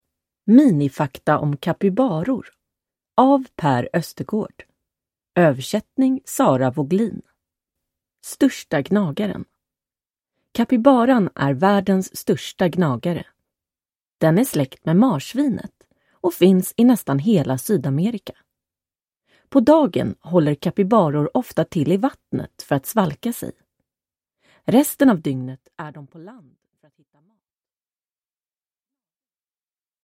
Minifakta om kapybaror – Ljudbok